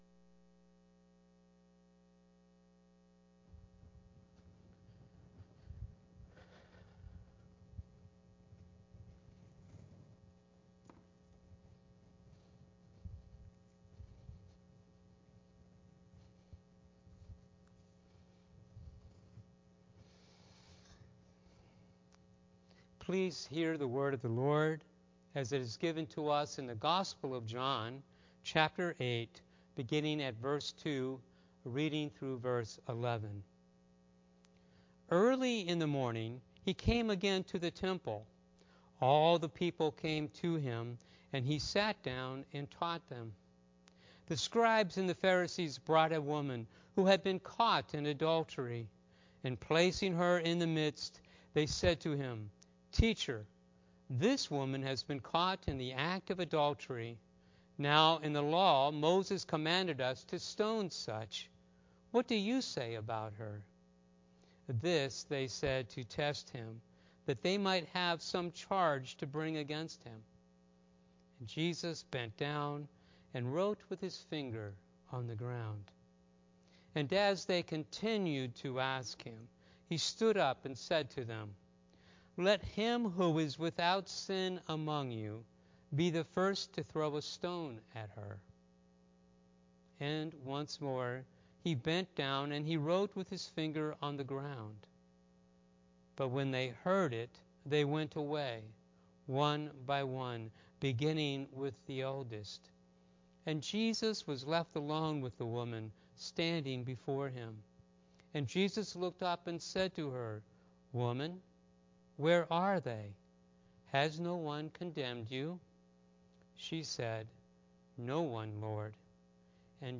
Online Sunday Service
Sermon